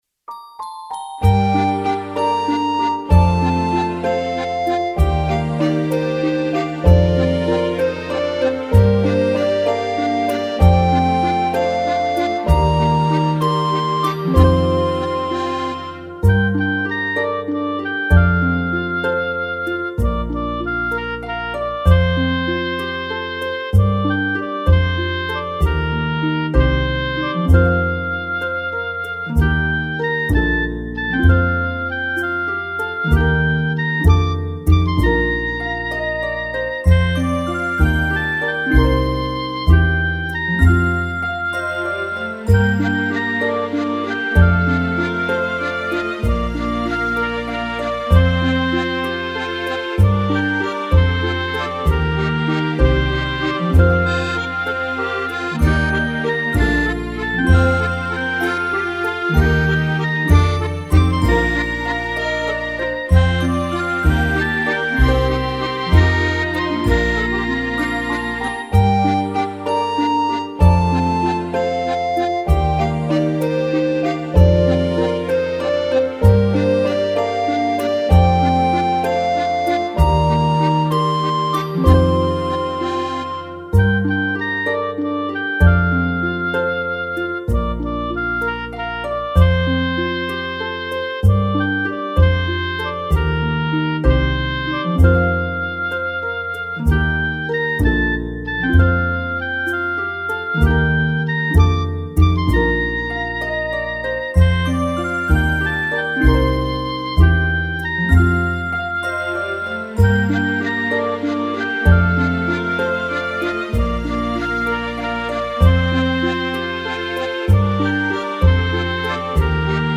毋好噭毋好噭(純伴奏版) | 新北市客家文化典藏資料庫